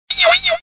Fichier:Cri 0459 DP.ogg